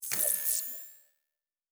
Sci-Fi Sounds / Electric / Device 5 Start.wav